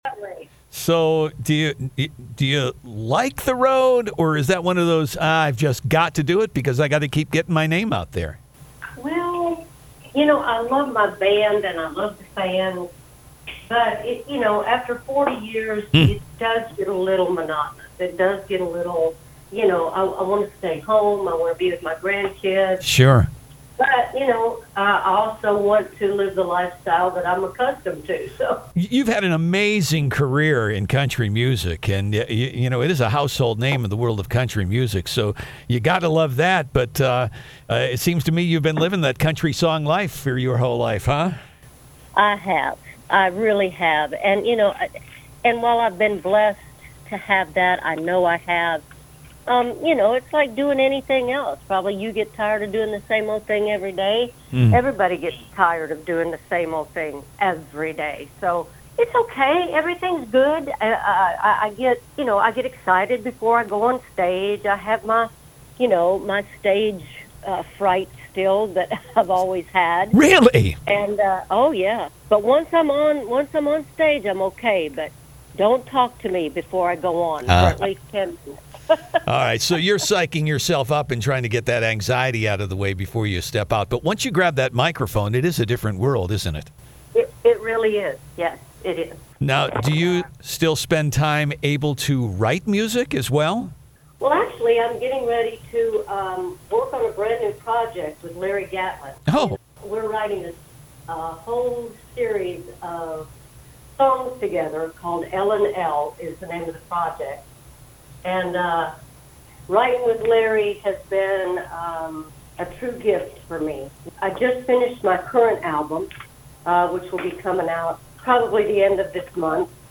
lorrie morgan interview